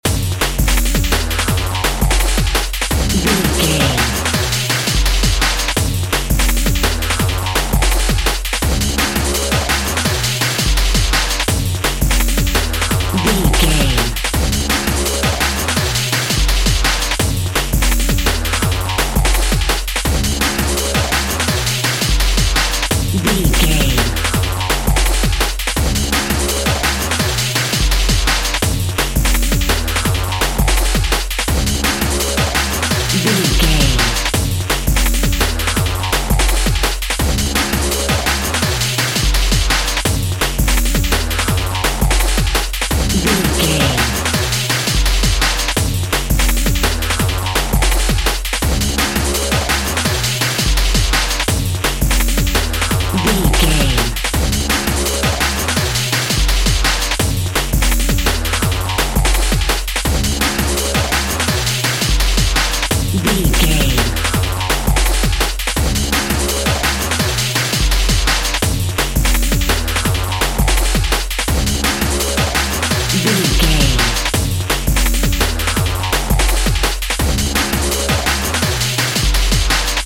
Aeolian/Minor
F#
Fast
futuristic
hypnotic
industrial
frantic
aggressive
dark
drums
synthesiser
break beat
sub bass
synth lead
synth bass